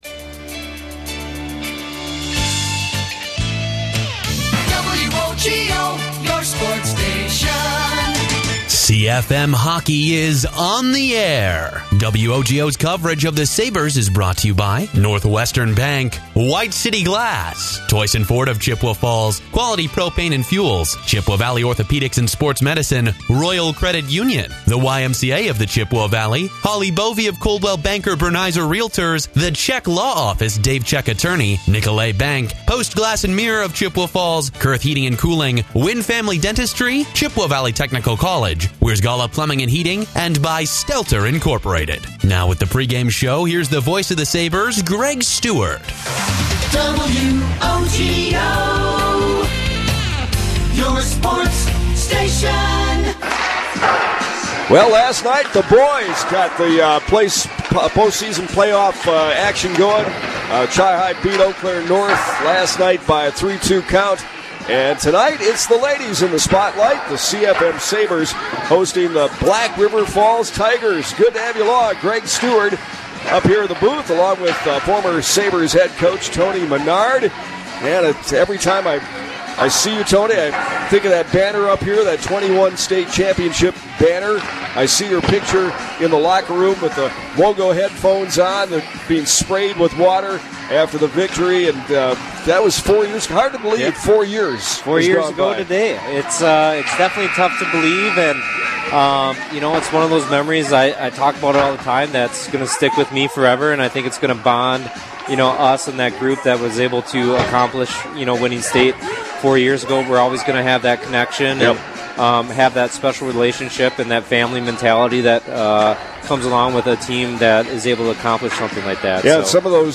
had the call on WOGO Radio as the Chippewa Falls/Menomonie Sabres played the The Black River Falls Tigers in the WIAA Regional at The Chippewa Ice Arena on 2/20/25